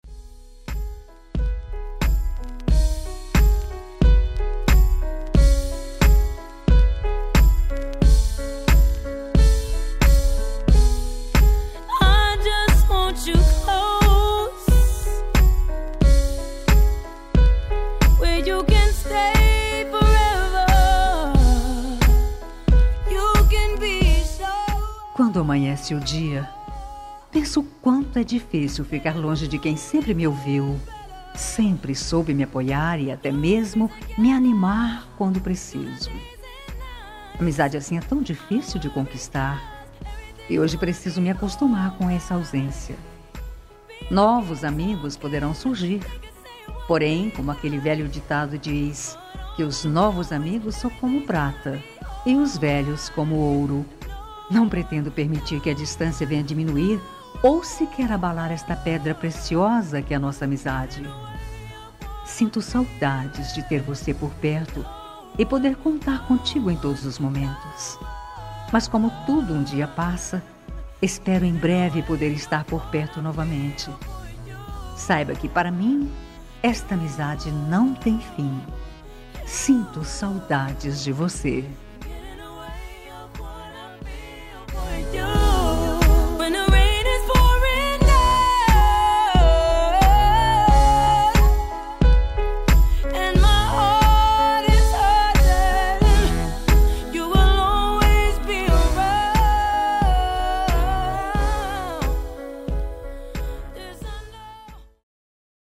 Telemensagem Amizade – Voz Feminina – Cód: 30321 – Distante
30321-amizade-distante-fem.m4a